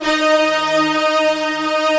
Index of /90_sSampleCDs/Optical Media International - Sonic Images Library/SI1_Fast Strings/SI1_Fast octave